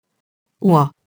oie [wa]